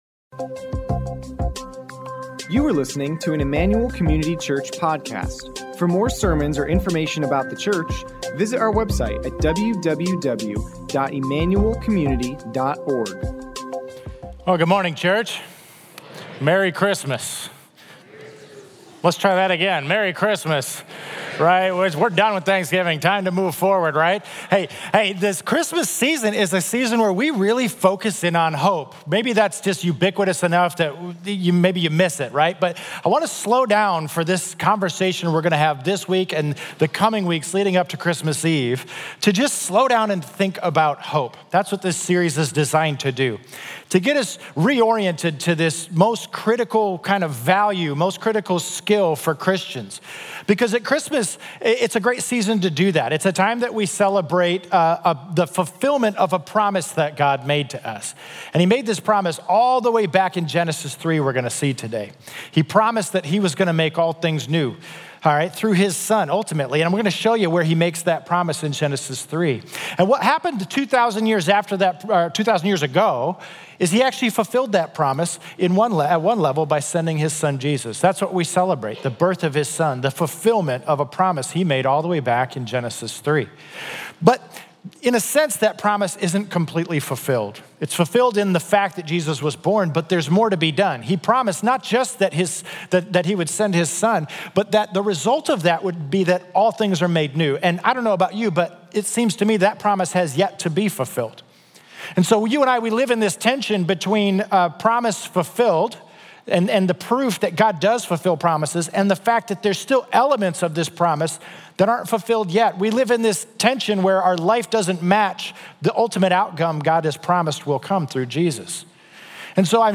Sermon Notes Completed Notes